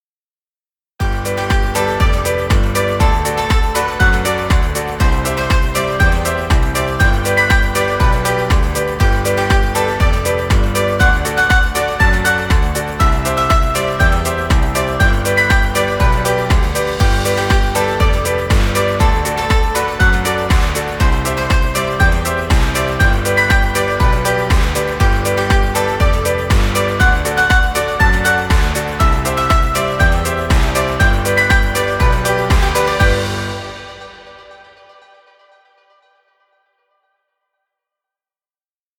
Children music. Background music Royalty Free.
Stock Music.